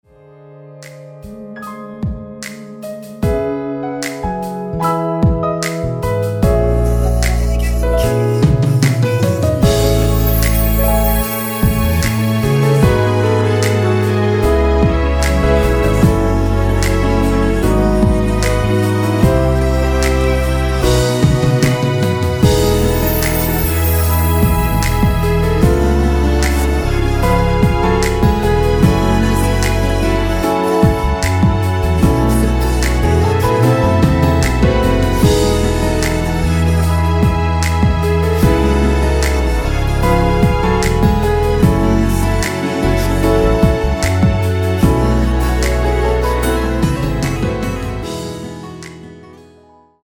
원키 코러스 포함된 MR 입니다.
앞부분30초, 뒷부분30초씩 편집해서 올려 드리고 있습니다.
중간에 음이 끈어지고 다시 나오는 이유는